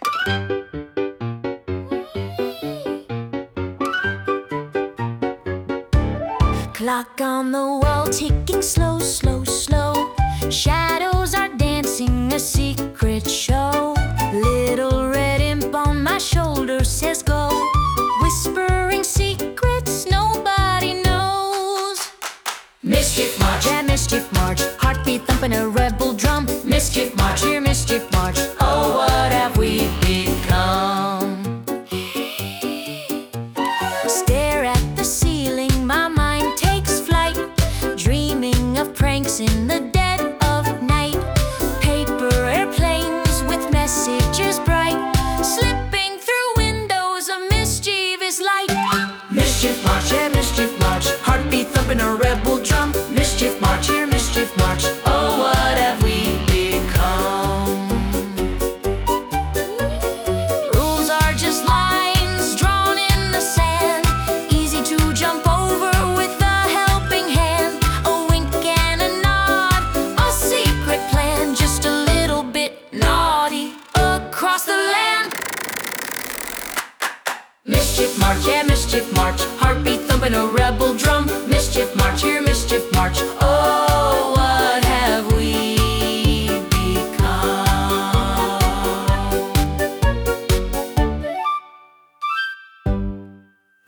🌍 Playful, cheerful, and full of fun!
brings a lively rhythm and a mischievous spirit,
ちょっぴりいたずらっ子なメロディと、ワクワクするリズムが楽しいキッズ向けの一曲です。
笑顔でステップを踏みたくなる、明るくポップなサウンドをお楽しみください✨